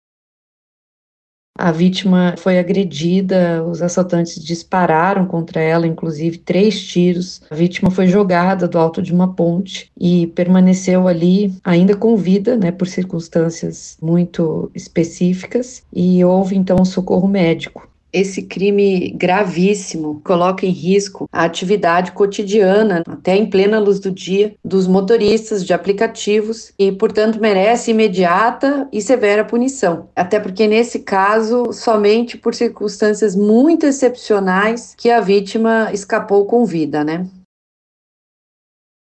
A promotora de justiça, Mariana Seifert Bazzo, destacou que a vítima foi agredida e que os assaltantes dispararam três tiros contra ela, antes de jogarem no Rio Iguaçu.